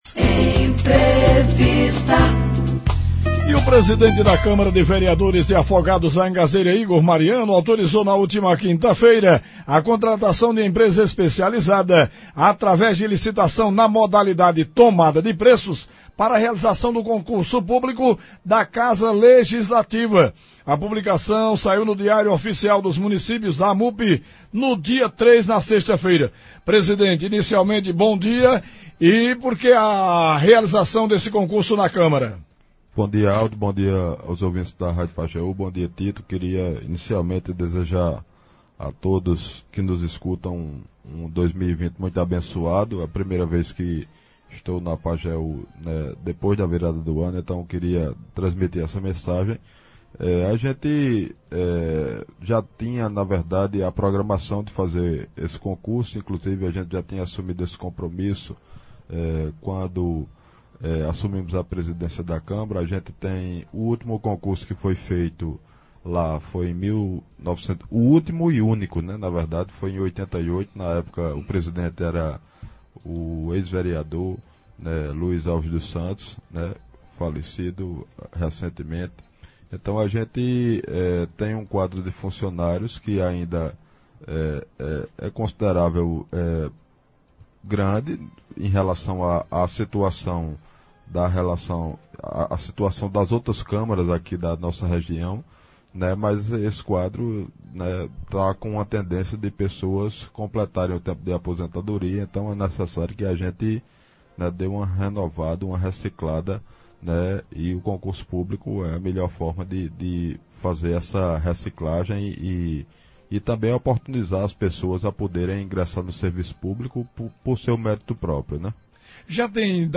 Em entrevista ao programa Manhã Total da Rádio Pajeú FM, desta segunda-feira (06.01), o presidente da Câmara de Vereadores de Afogados da Ingazeira, Igor Sá Mariano, deu mais detalhes sobre o concurso público que será realizado na Casa. Ouça abaixo a íntegra da entrevista: